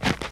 snow-07.ogg